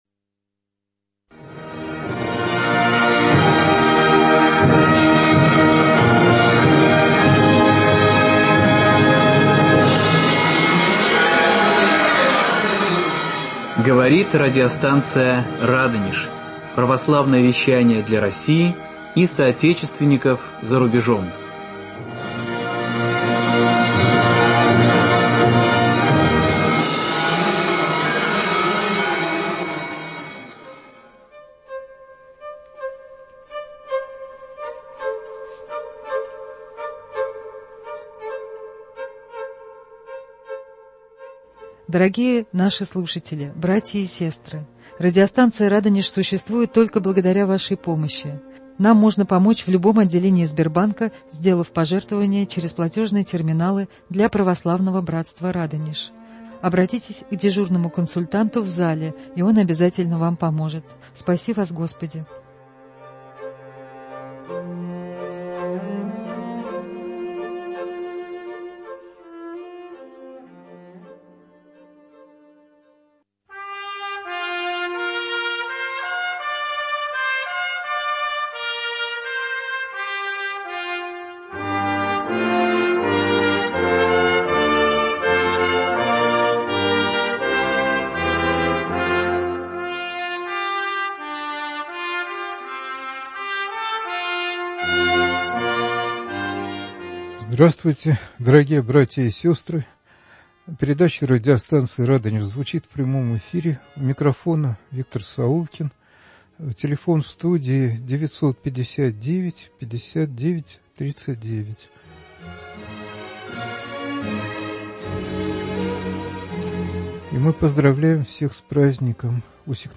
Прямой эфир.